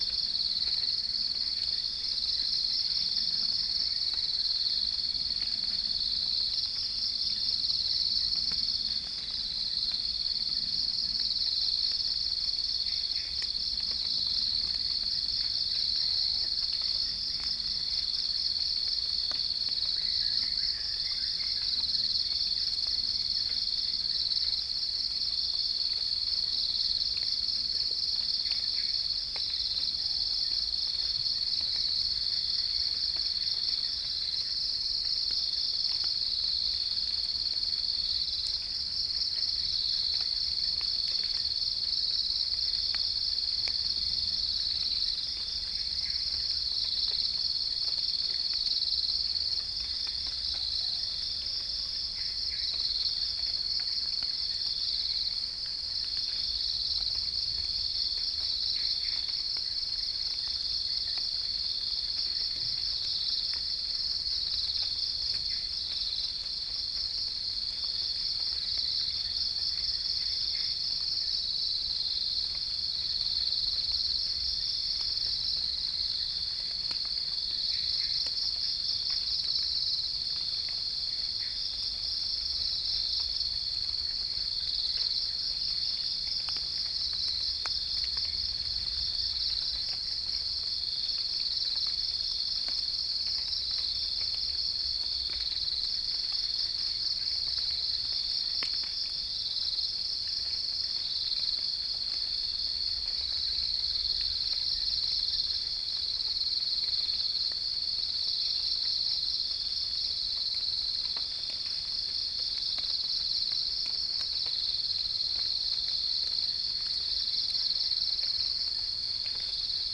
Gallus gallus
Spilopelia chinensis
Geopelia striata
Pycnonotus goiavier